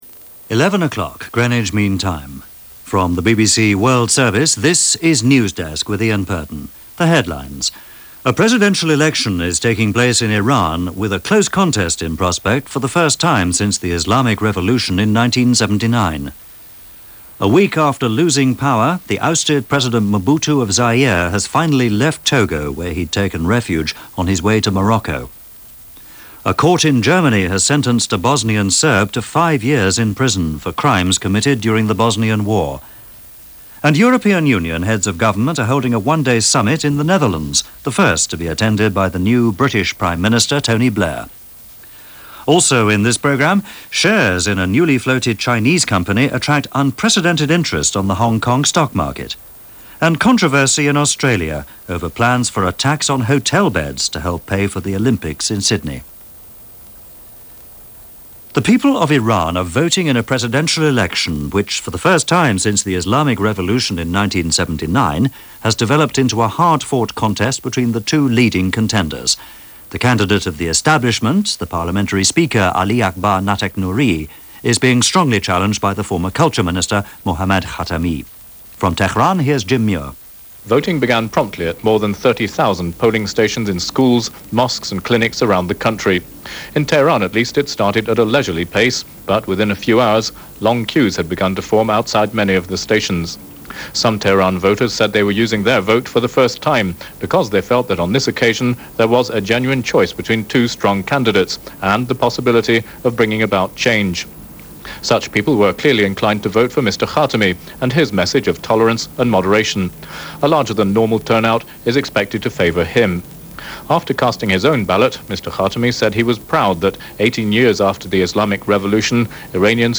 International news, this May 23, 1997 as presented by the BBC World Service.